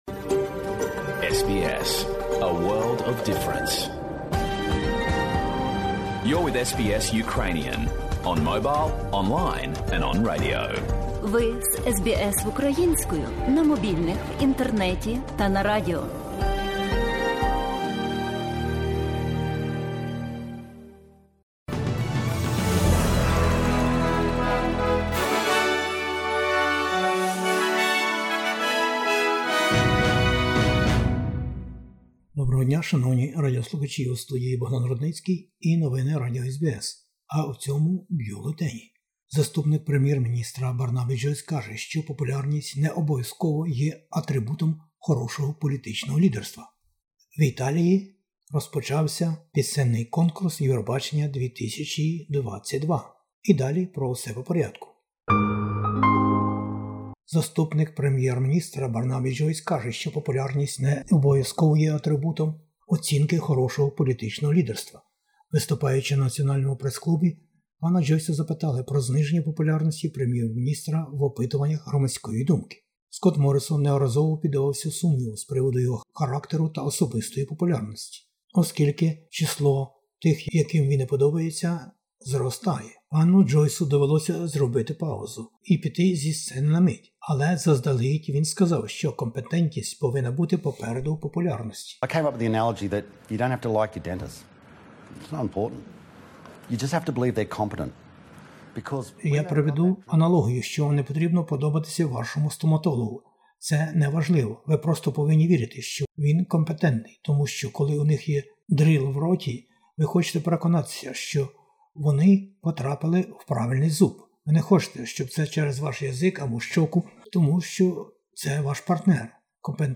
Бюлетень SBS новин українською мовою. Федеральні вибори-2022: політичні баталії продовжуються поміж коаліцією та лейбористами. США збільшили допомогу Україні.